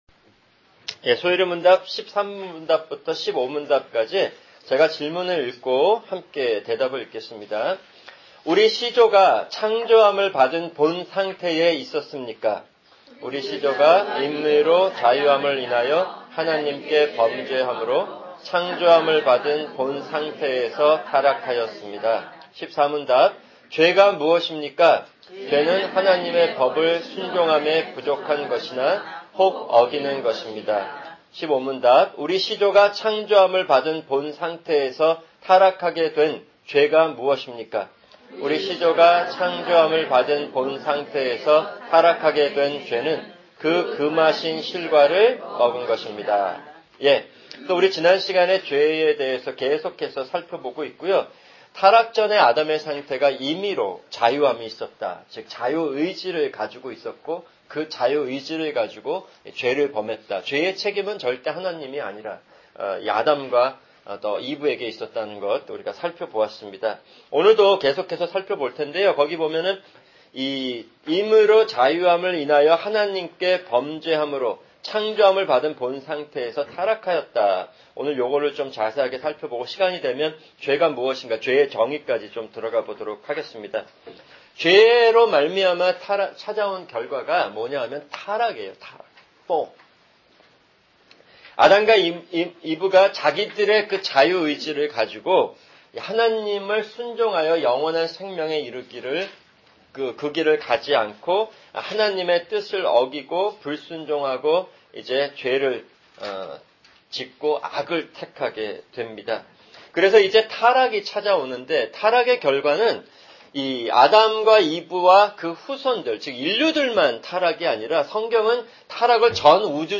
[주일 성경공부] 소요리문답(52) 제13-15문답(3)